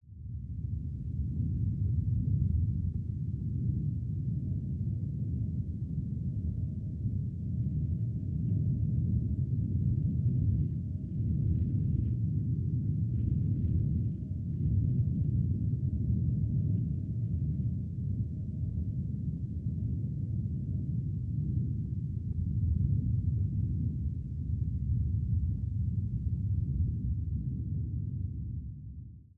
Тихий звук, доступний для глухонімих